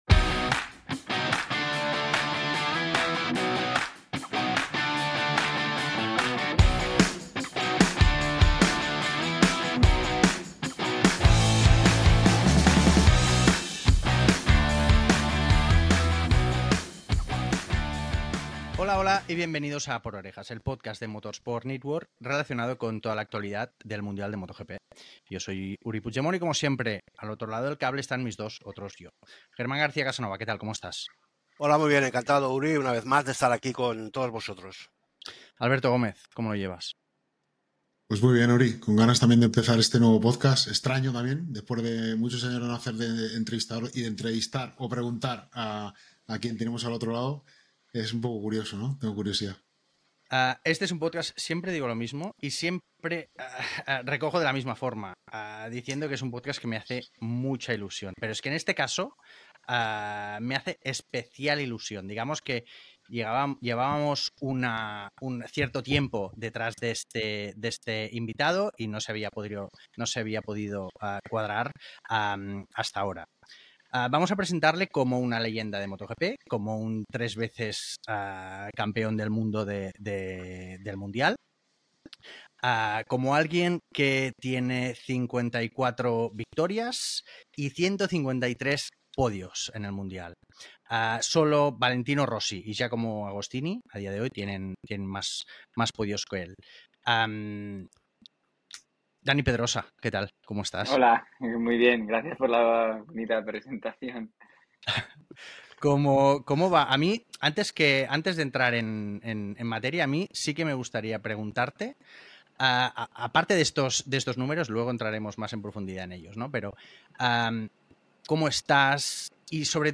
El tricampeón del mundo y MotoGP Legend, el piloto español Dani Pedrosa, es el invitado estrella del Podcast 'Por Orejas' de Motorsport Network en su edición del verano.